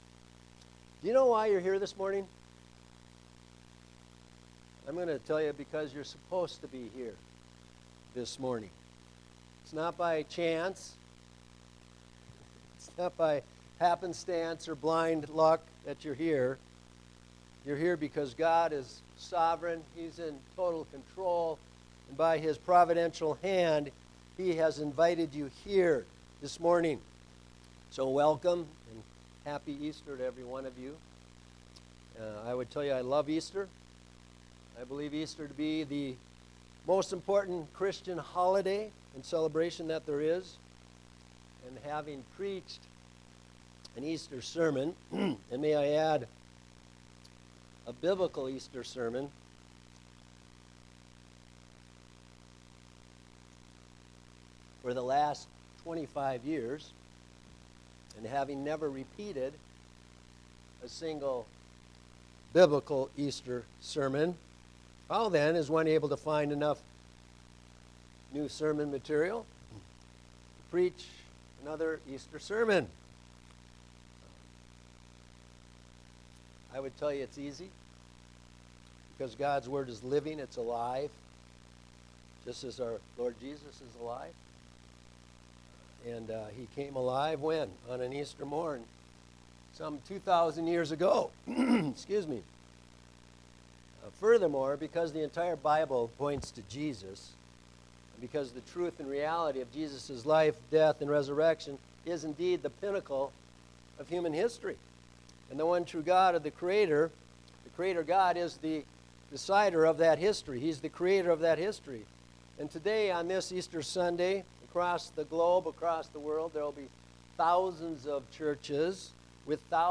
An Easter Sermon